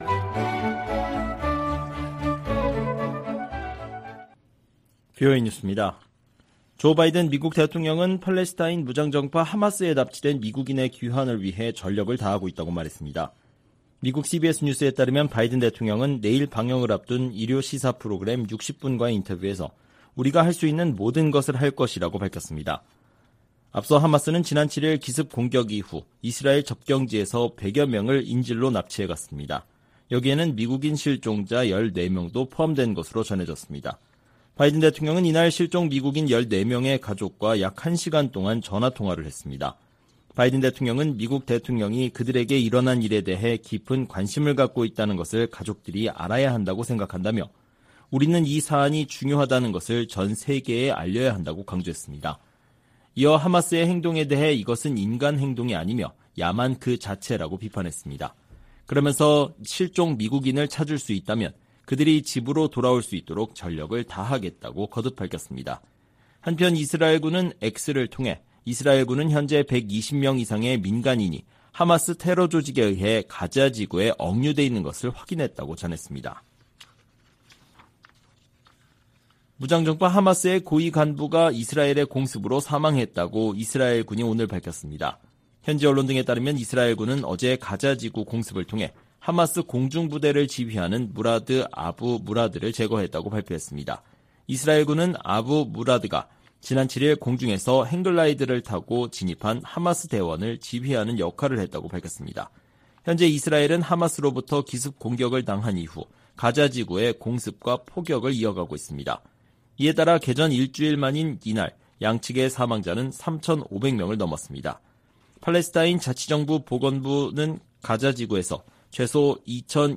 VOA 한국어 방송의 토요일 오후 프로그램 2부입니다.